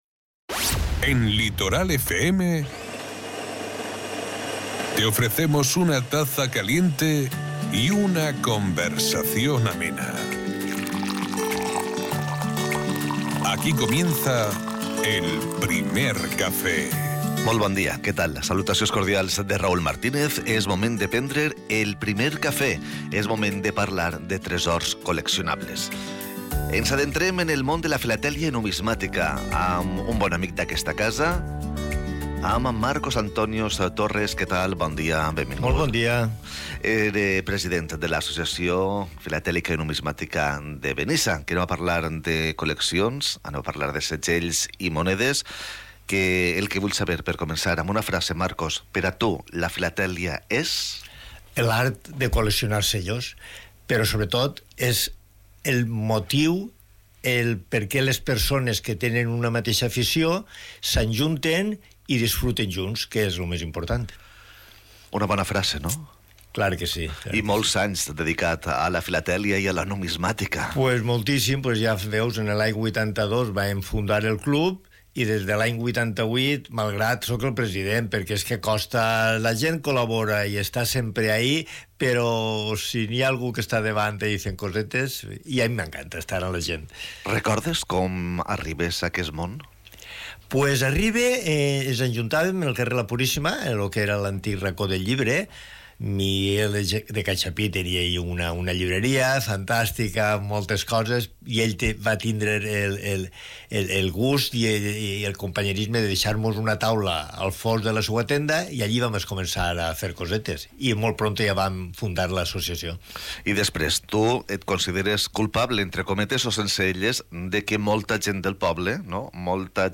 el primer café de Radio Litoral ha tenido como invitado especial